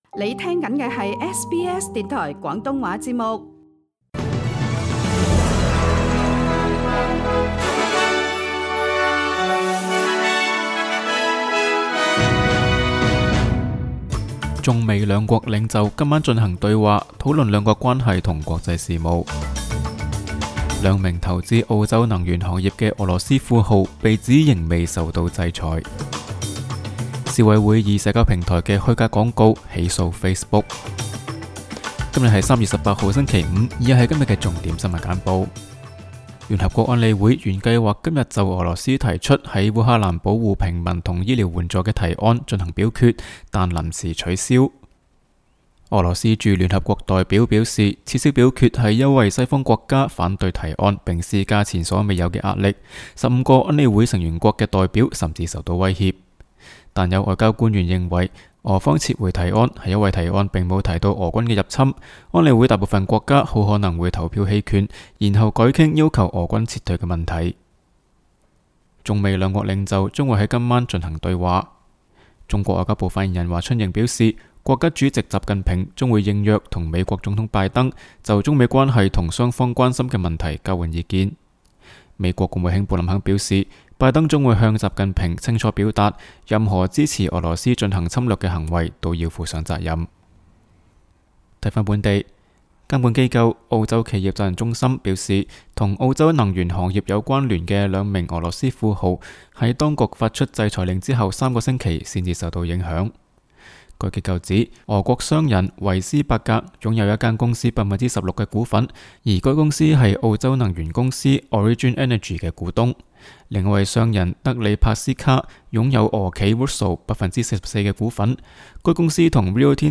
SBS 新闻简报（3月18日）